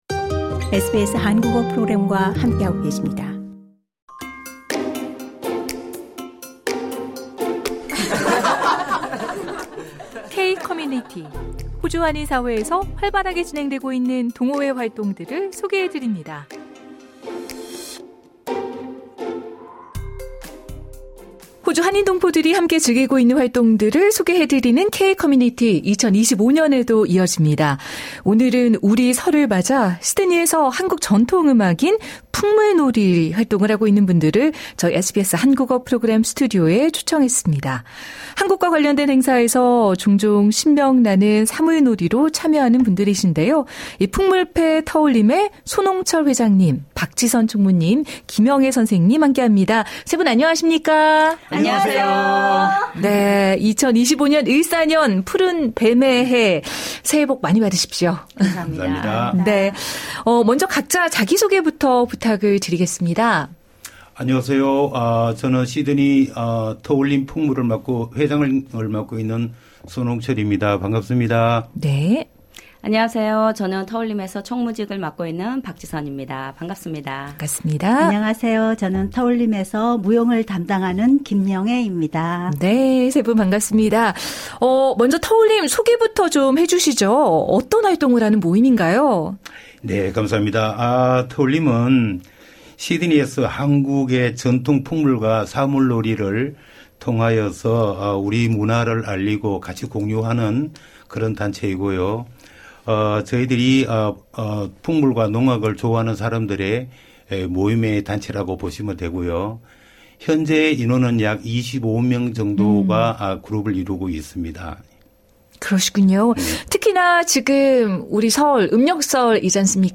오늘은 우리 설을 맞아 시드니에서 한국 전통 음악인 풍물놀이를 함께하고 있는 분들을 저희 SBS 한국어 프로그램 스튜디오에 초청했는데요.